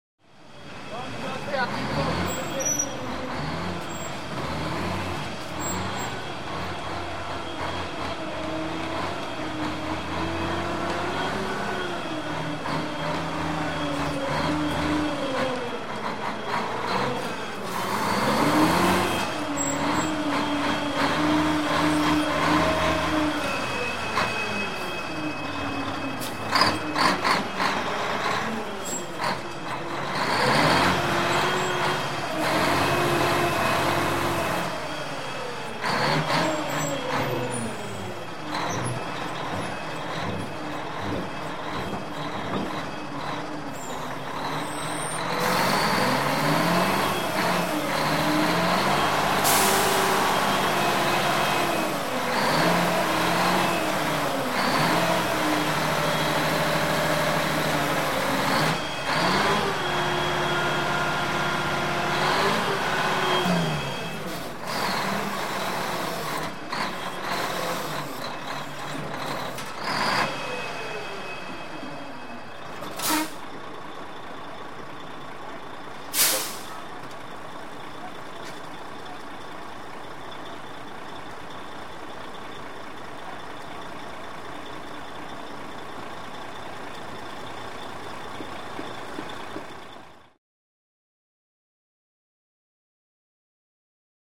Звуки мусоровоза
Звук где мусоровоз маневрирует между узких машин во дворе (не может спокойно проехать) (01:34)